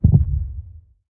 Minecraft Version Minecraft Version 1.21.4 Latest Release | Latest Snapshot 1.21.4 / assets / minecraft / sounds / mob / warden / heartbeat_4.ogg Compare With Compare With Latest Release | Latest Snapshot
heartbeat_4.ogg